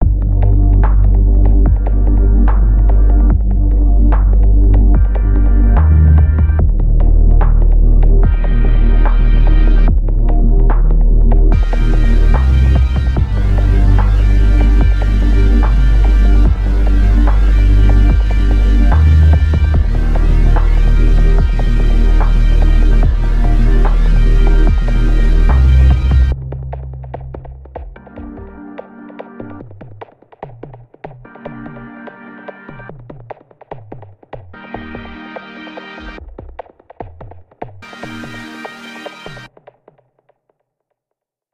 “Closure” resolves around a rather simple beat, high frequencies of the kick and the snare are cut off to let more room for the main synthesizer that is taking the lead in this short preview. If you listen with headphones you can hear how the bass line is pulsing and shifting around in pitch.